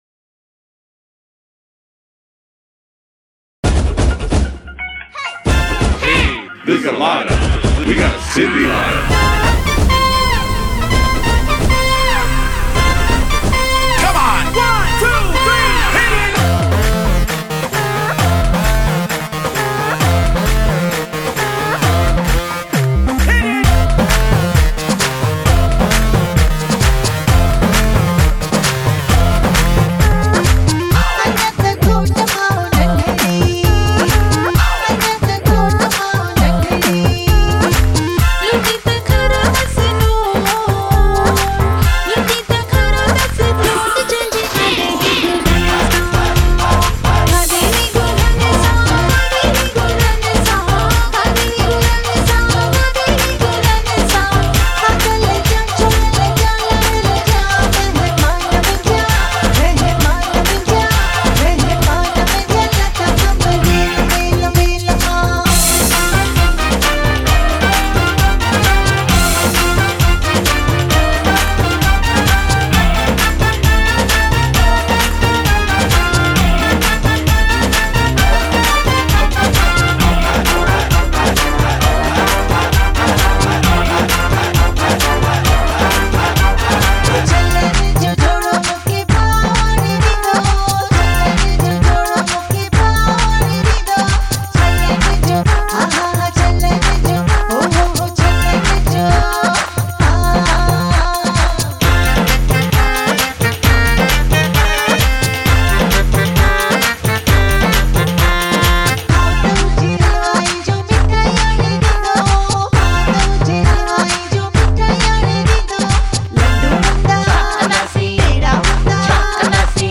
Rap Singer
Backing Vocals